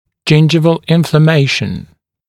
[‘ʤɪnʤɪvəl ˌɪnflə’meɪʃ(ə)n] [ʤɪn’ʤaɪvəl][‘джиндживэл ˌинфлэ’мэйш(э)н] [джин’джайвэл]воспаление десны